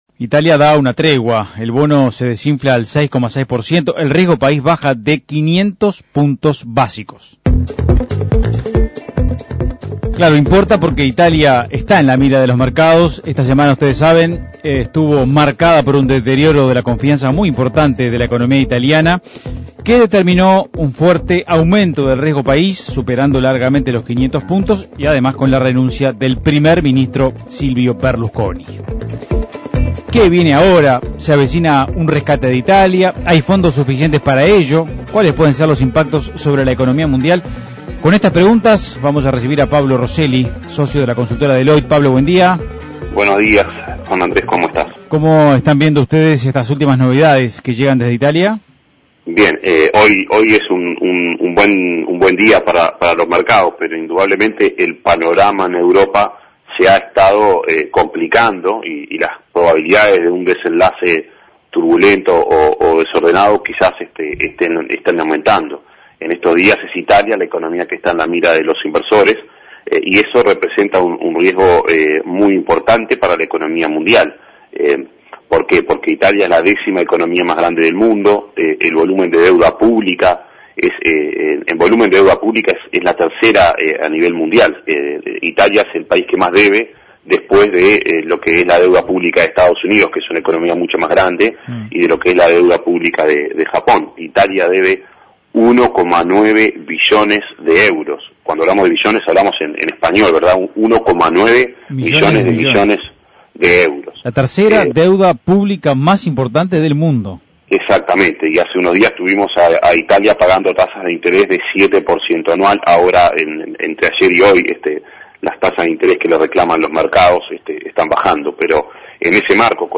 Análisis Económico Italia en la mira de los mercados: ¿se avecina un "rescate" de esa economía?